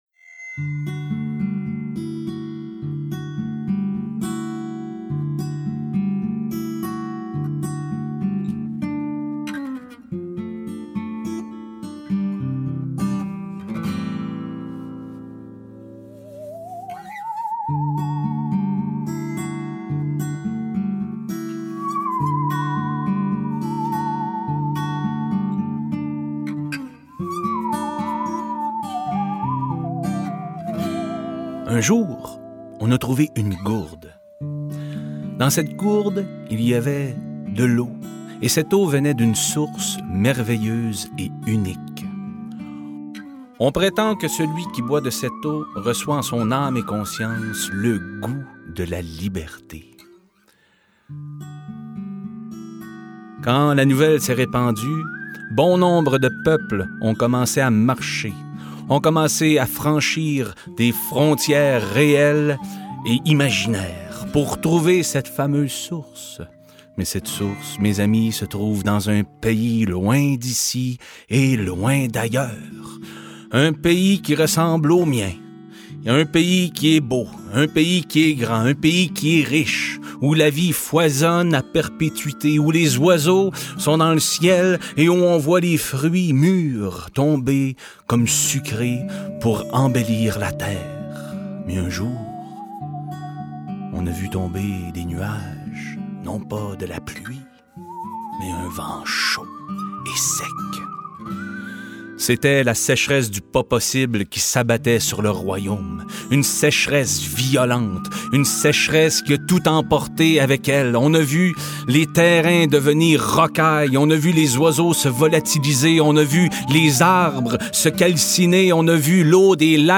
Contes d’auteurs